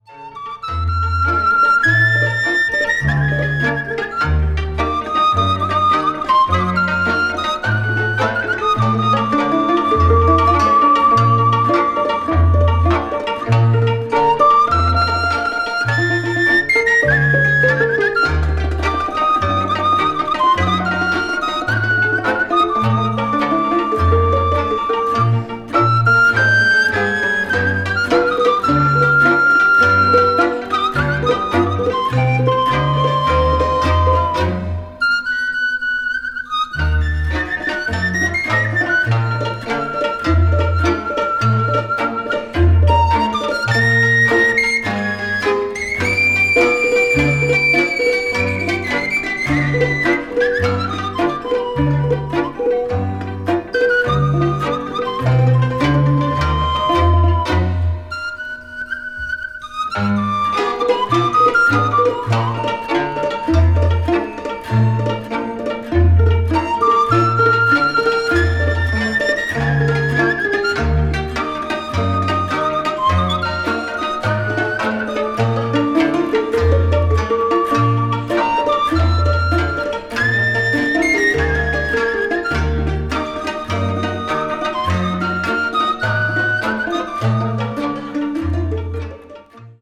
media : EX/EX(some slightly noises.)
a nai (pan flute) player born into a Gypsy family in Sibiu
acoustic   balkan   ethnic music   romania   traditional